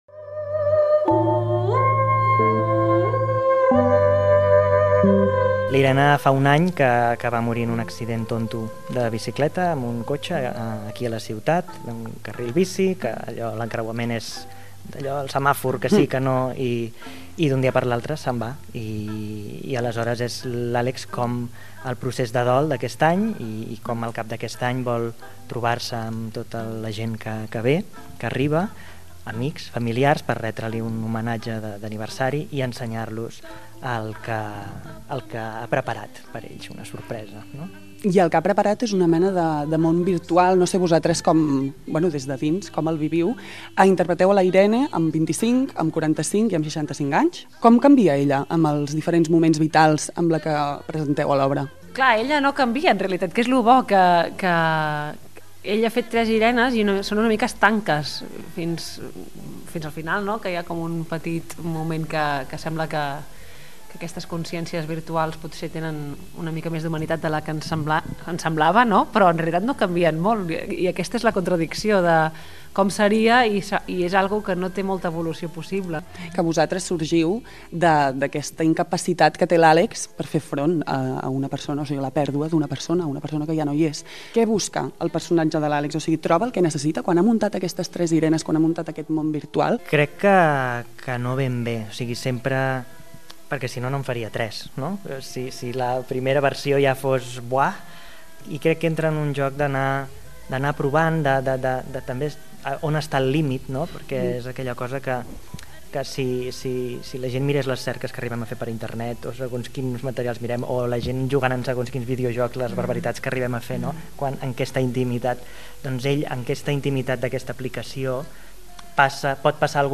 ENTREVISTA. 'Arribaràs i serà de nit'